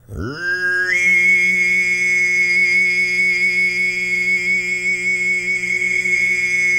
TUV1 DRONE13.wav